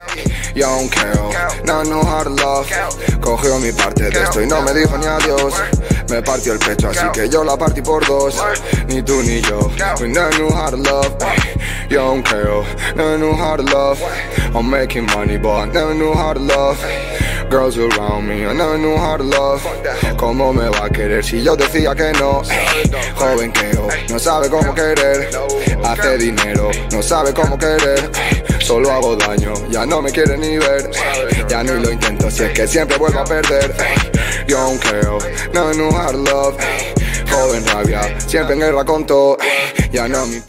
Siempre los últimos tonos de Reguetón